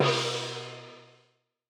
Gong-Medium.wav